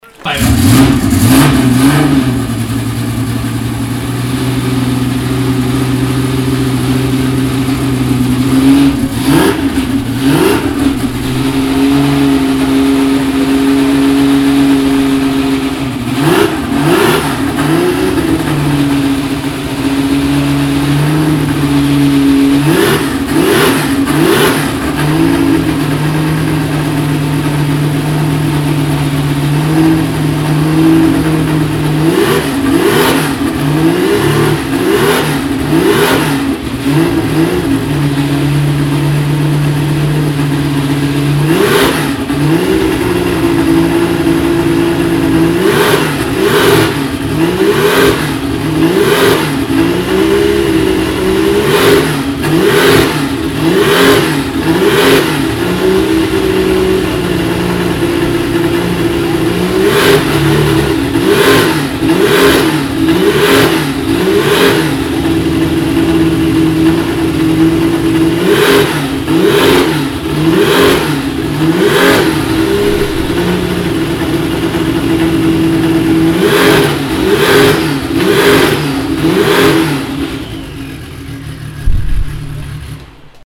Porsche 936/77 Spyder (1977) an der Porsche Soundnacht 2016